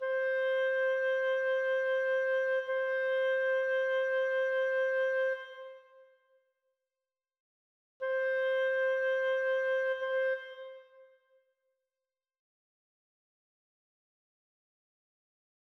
Toward the goal of explaining and demonstrating what reverberation does, I created three WAVE files of the same notes in your example, where the instrument is the Notion 3 bundled Clarinet . . .
(2) This is the Notion 3 bundled Clarinet with Notion 3 Reverb in its default settings, so it is the "wet" Clarinet:
Notion 3 Bundled Clarinet -- WET -- Notion 3 Reverb (Default) -- WAVE file (2.8MB, approximately 16 seconds)
N3-Clarinet-Wet.wav